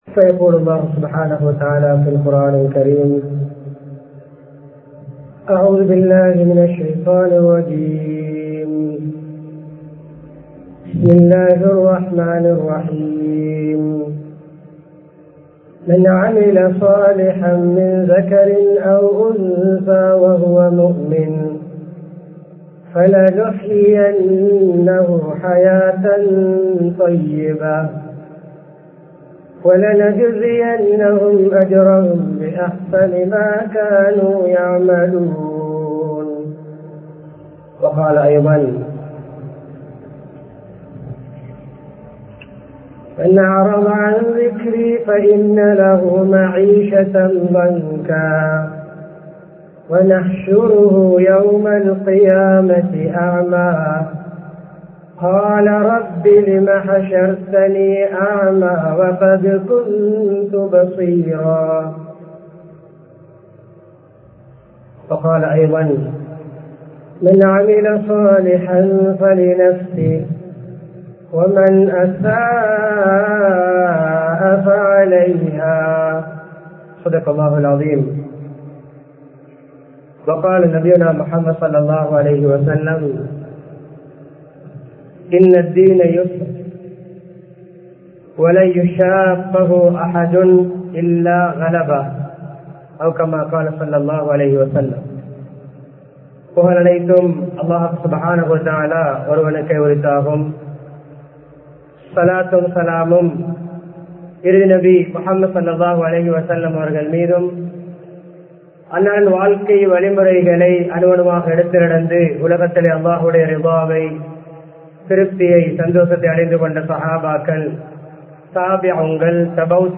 ஆன்மீகத்தின் அவசியம் | Audio Bayans | All Ceylon Muslim Youth Community | Addalaichenai
Colombo 10, Pichus Lane, Muhiyadeen Jumua Masjidh